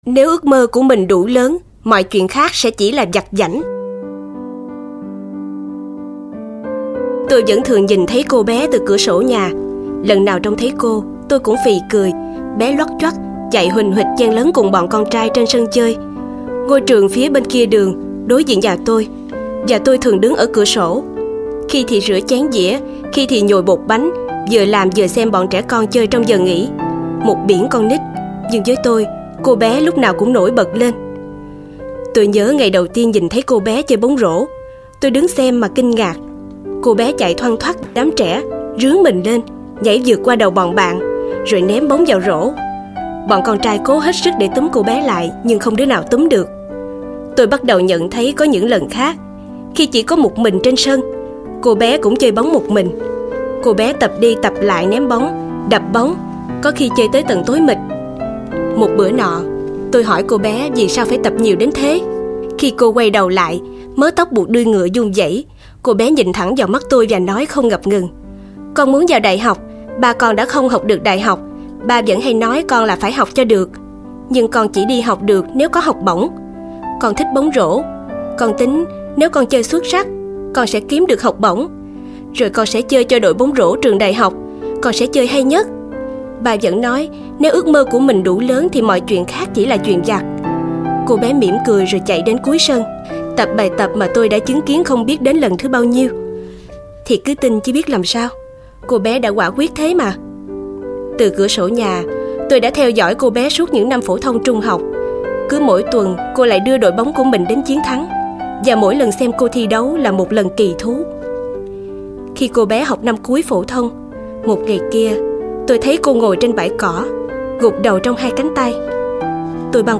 Sách nói Hãy Can Đảm Và Tốt Bụng - Sách Nói Online Hay